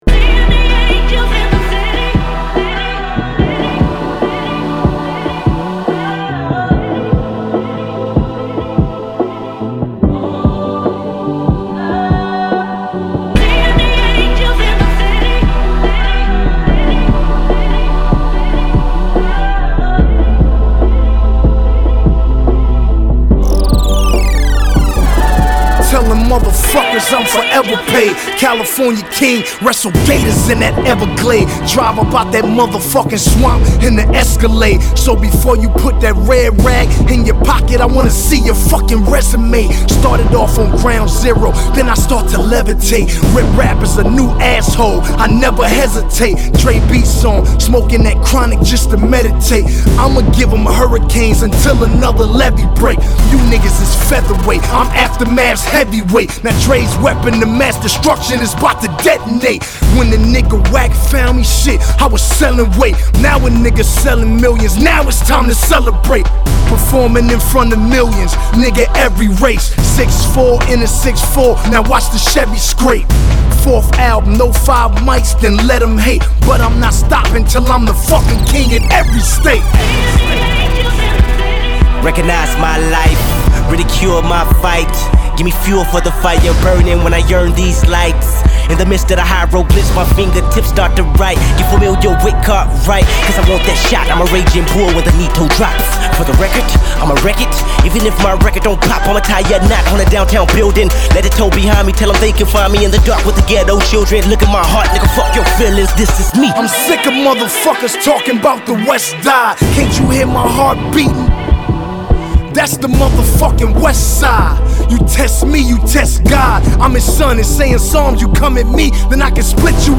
dramatic production
With a movie-like atmosphere set